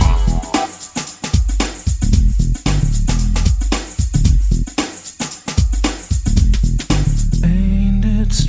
• 113 Bpm Nineties Breakbeat Sample G Key.wav
Free drum groove - kick tuned to the G note.
113-bpm-nineties-breakbeat-sample-g-key-Non.wav